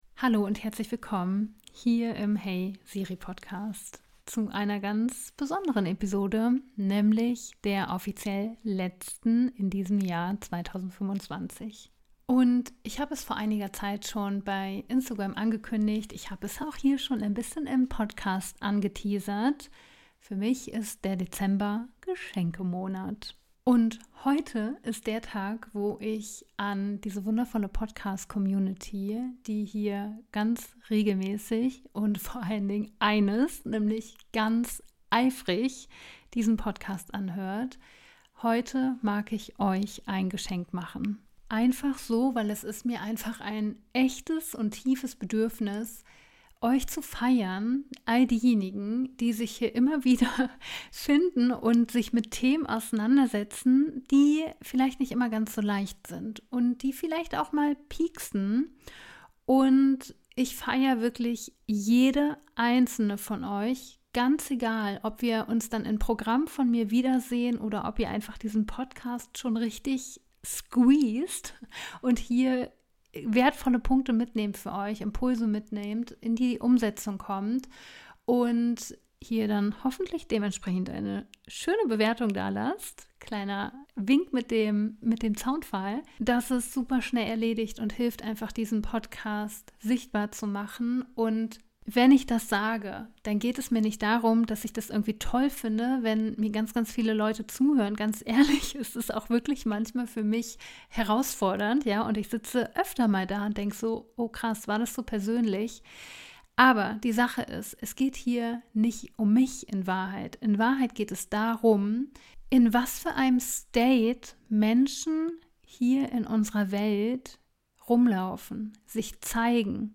Geh-Meditation für einen sanften Abschied vom Jahr 2025
Du findest hier eine geführte Gehmeditation, die dich einlädt, dein Jahr nicht im Kopf zu bewerten, sondern im Körper zu würdigen ...